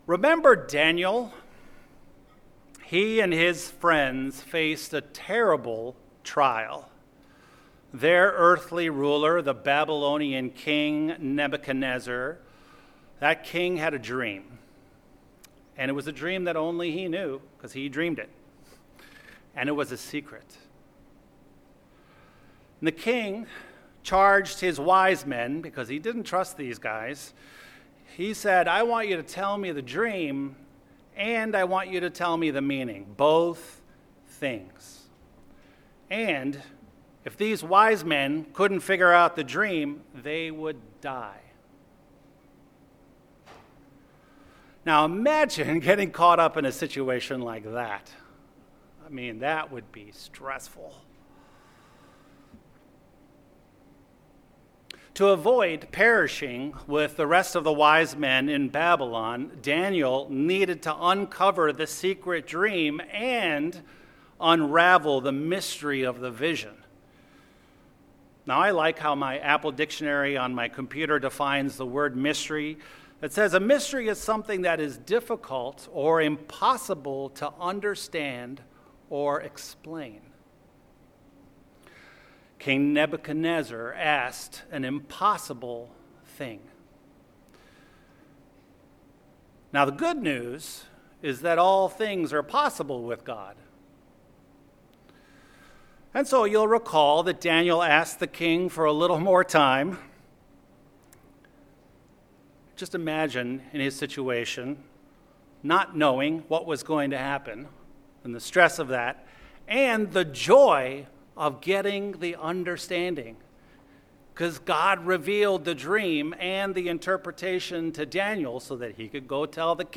Sermons
Given in Chicago, IL